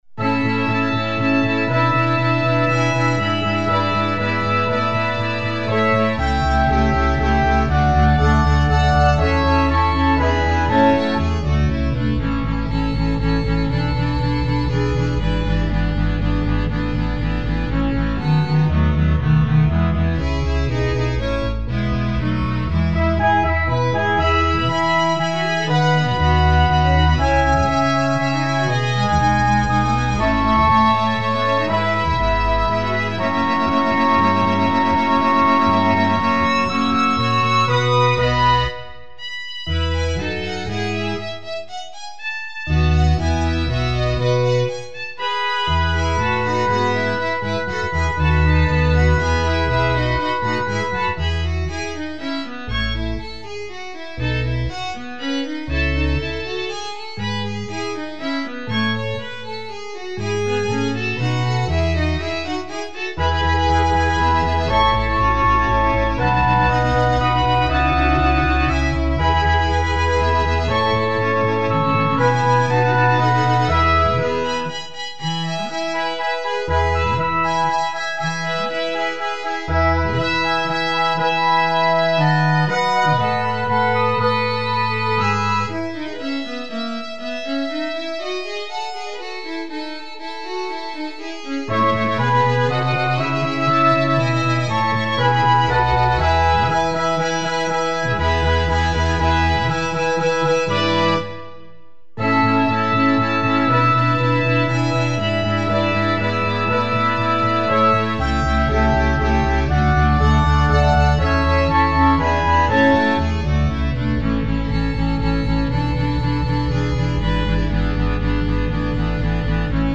Orchestra  (View more Intermediate Orchestra Music)
Classical (View more Classical Orchestra Music)